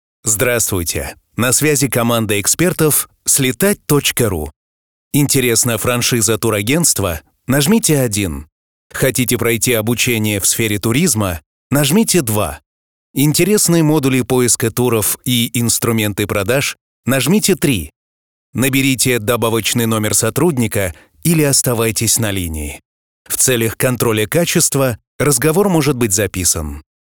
Автоответчик